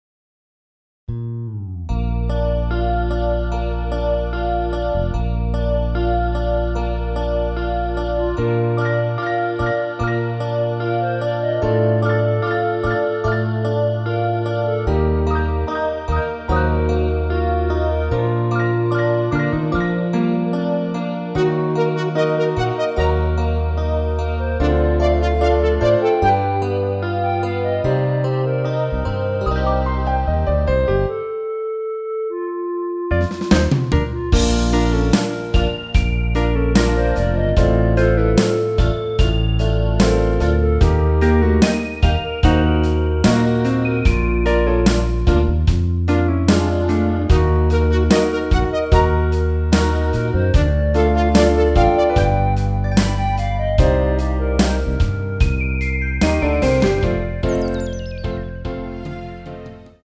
엔딩이 페이드 아웃이라 라이브하기 좋게 엔딩을 만들어 놓았습니다.
원키 멜로디 포함된 MR입니다.
Bb
앞부분30초, 뒷부분30초씩 편집해서 올려 드리고 있습니다.